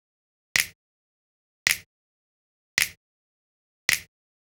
FingerSnapBeat.wav